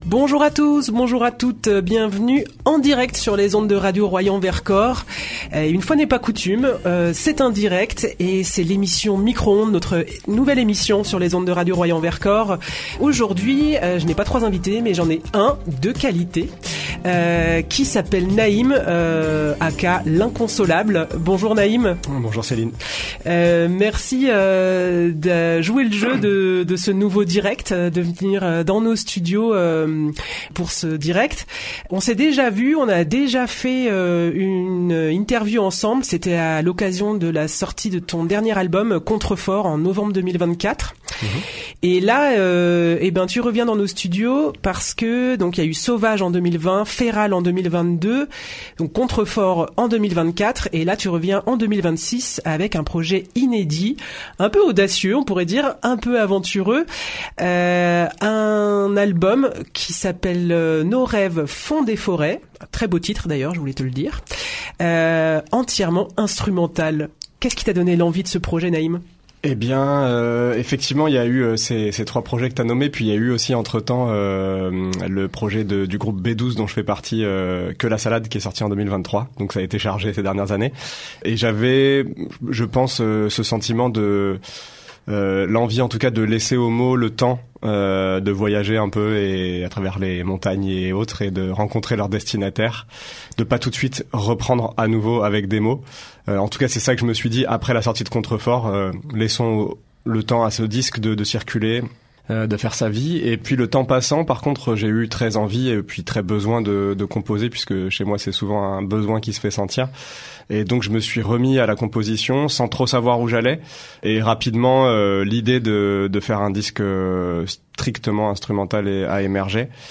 revient à notre micro dans le cadre de Micro-ondes, le nouveau direct de Radio Royans Vercors